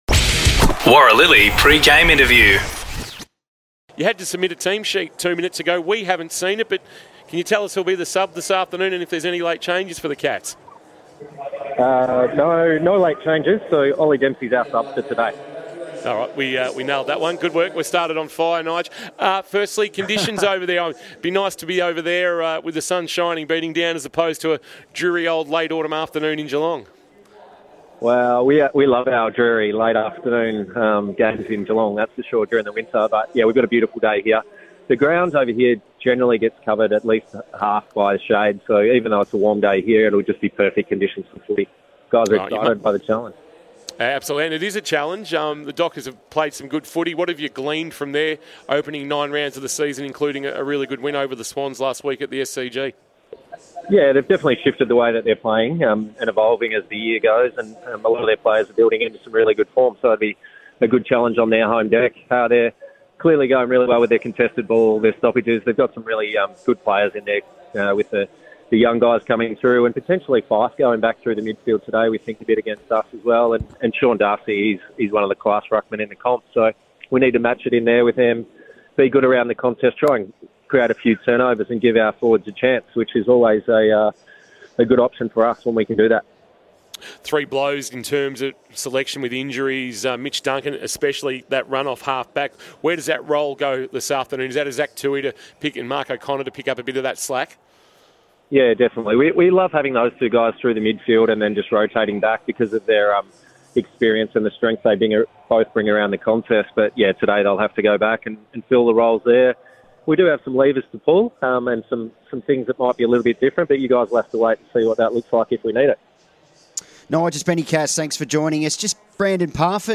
2023 - AFL - ROUND 10 - FREMANTLE vs. GEELONG: Pre-match Interview